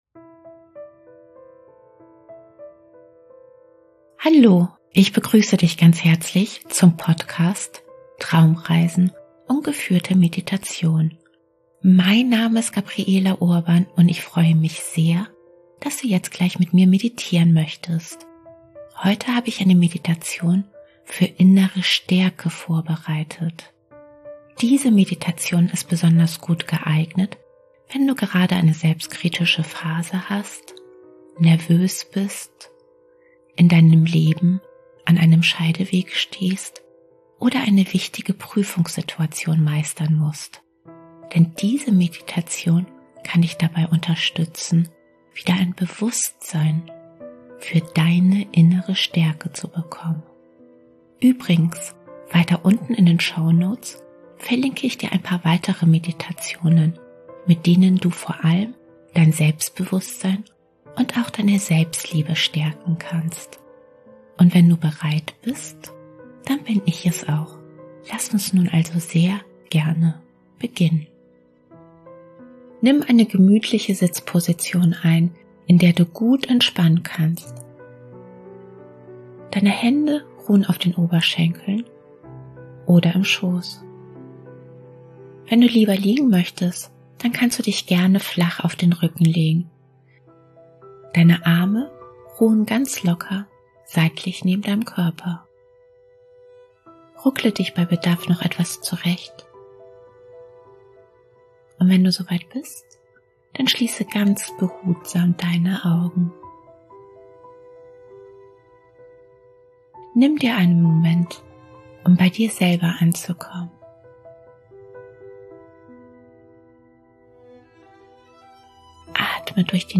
#046: Meditation innere Stärke – kurze Traumreise zum Riesen